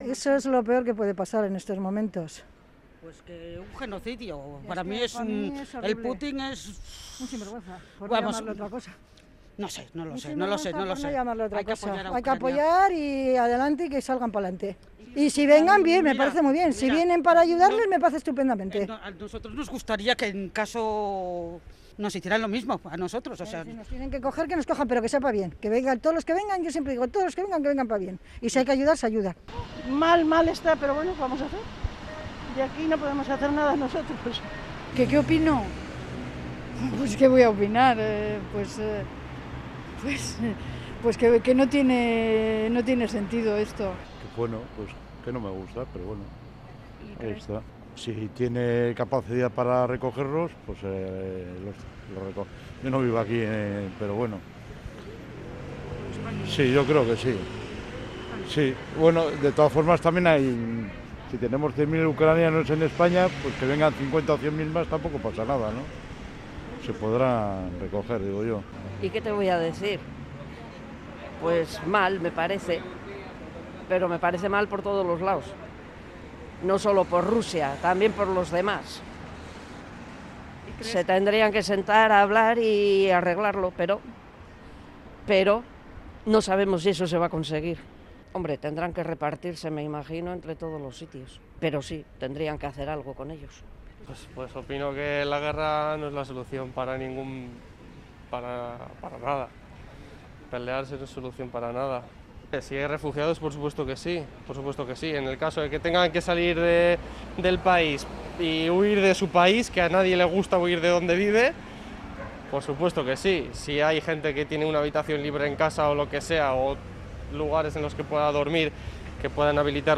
Onda Vasca ha salido a la calle a conocer la opinión de la ciudadanía acerca del conflicto de Ucrania